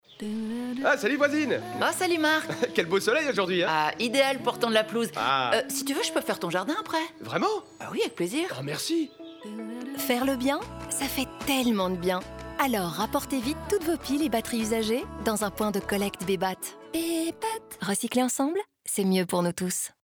Sound Production & Sound Design: La Vita Studios
250324-Bebat-radio-mix-OLA--23LUFS-jardin-FR-20.mp3